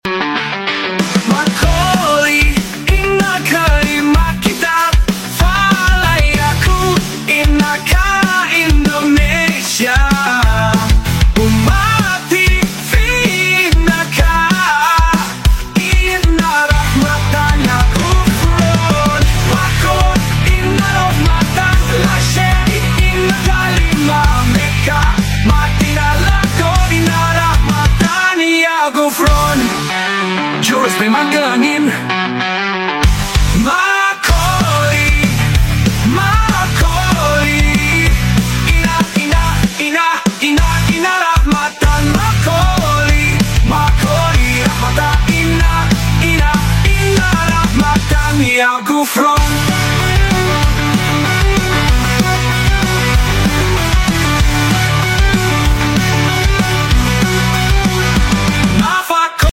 (voice samples) Music : AI